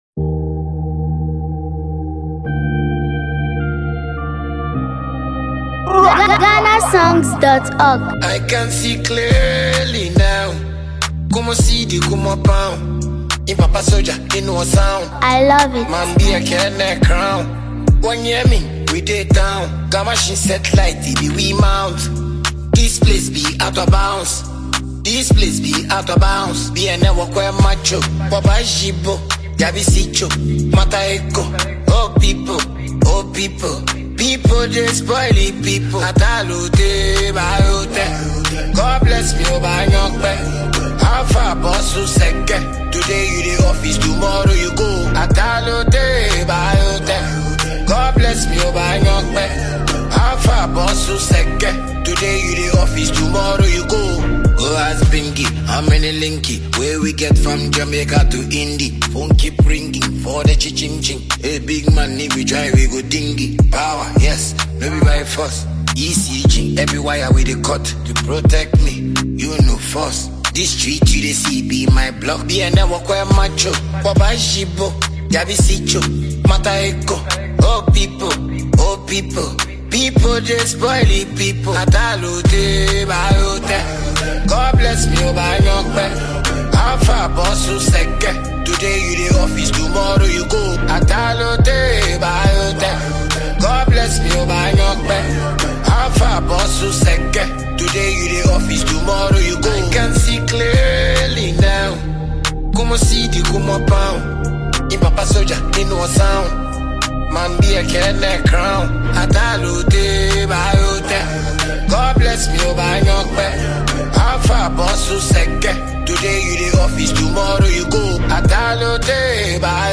energetic dancehall music